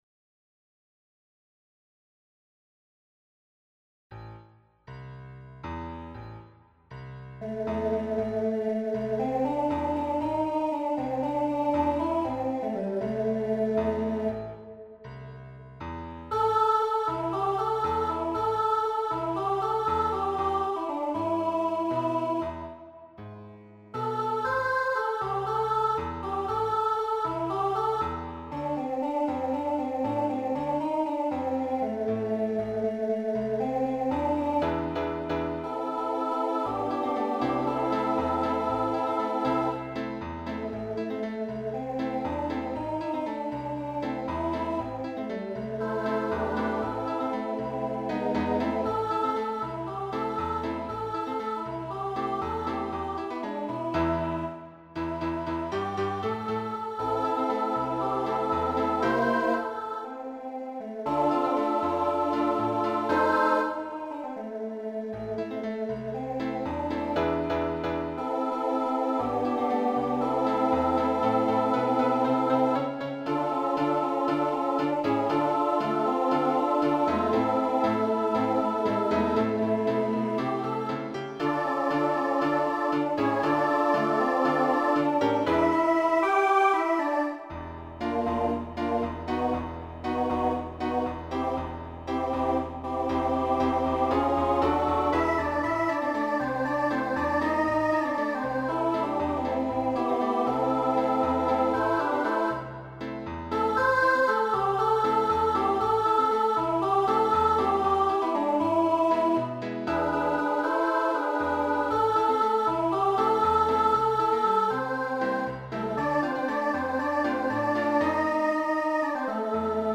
SSA Instrumental combo
Rock Decade 1960s Show Function Mid-tempo